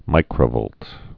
(mīkrə-vōlt)